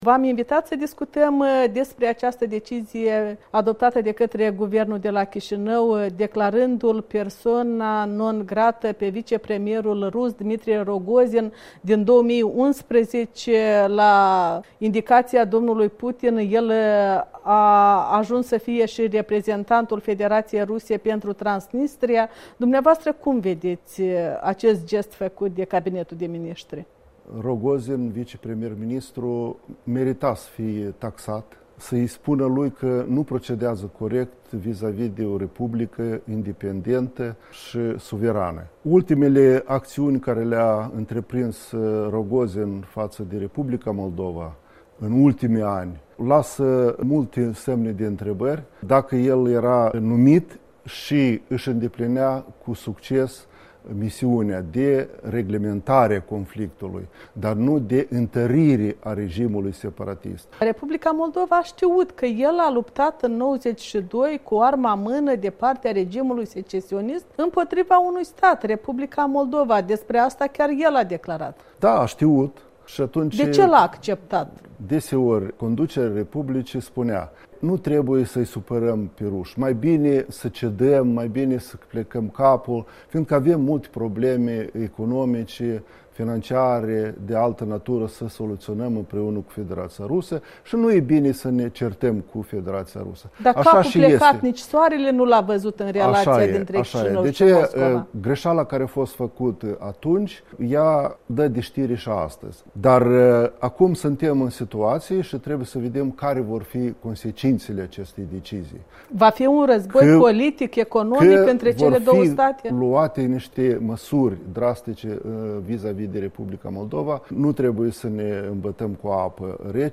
Interviu cu fostul director adjunct al Serviciului de Informații și Securitate.